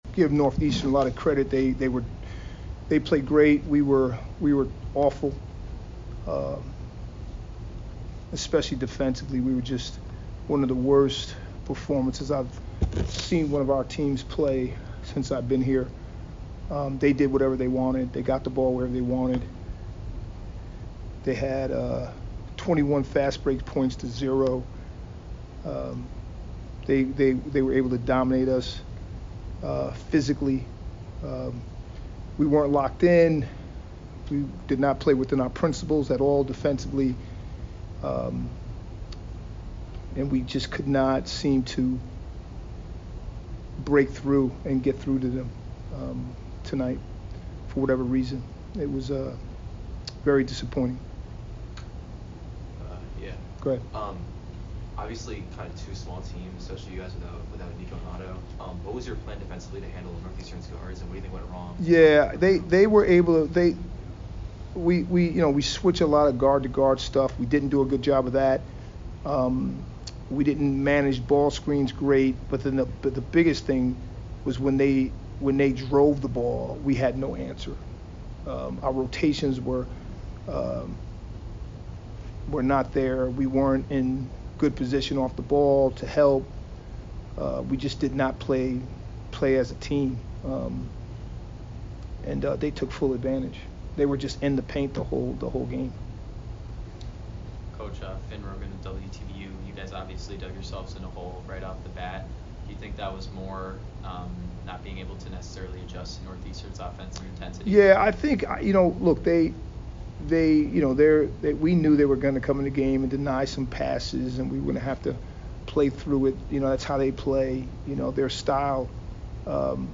Men's Basketball / Northeastern Postgame Interview (11-4-24)